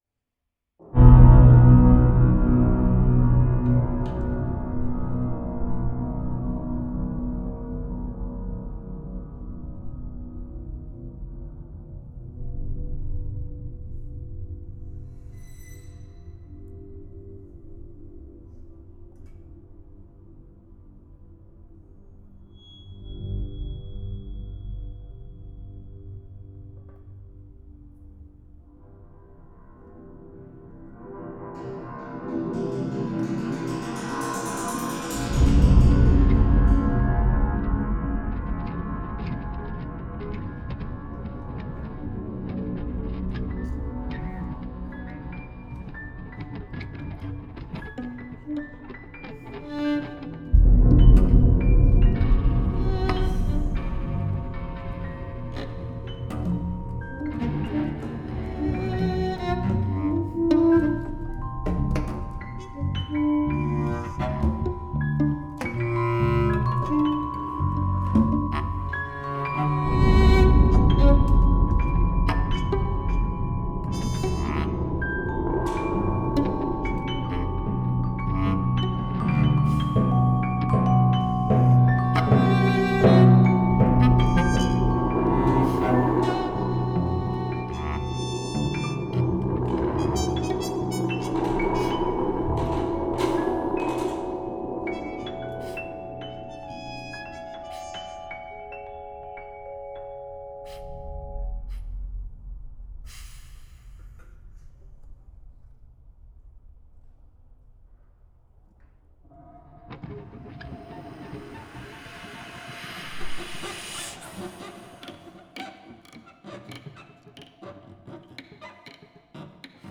pour dix instruments et bande sonore